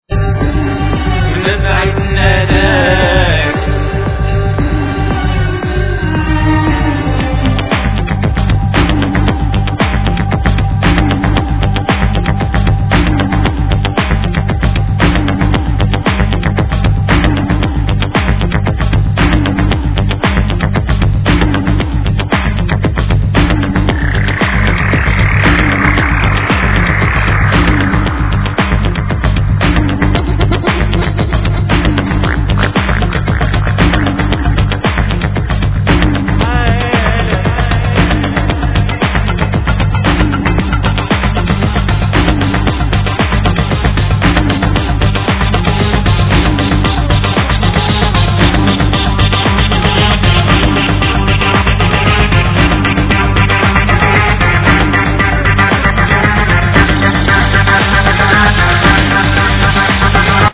Voclas, Oud, Darbukka
Violin
Keyboards
Background Vocals
Bass
Drums